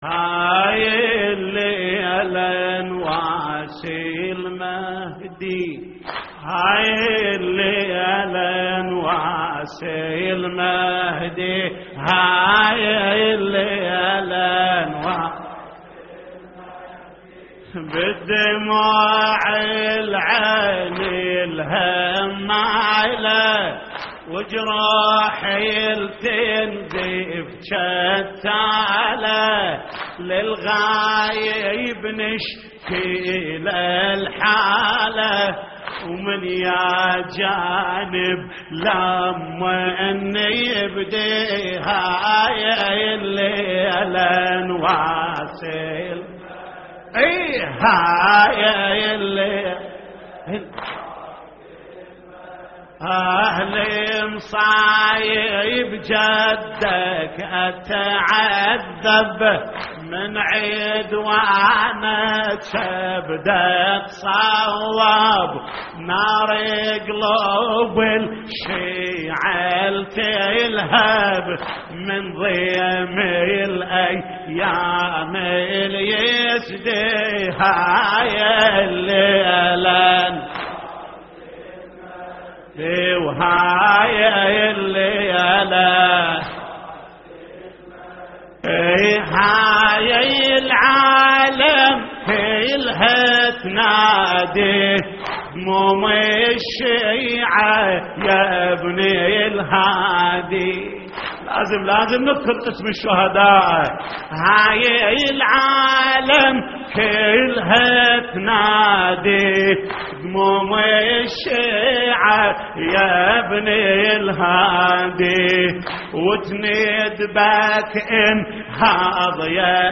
تحميل : هاي الليلة نواسي المهدي / الرادود جليل الكربلائي / اللطميات الحسينية / موقع يا حسين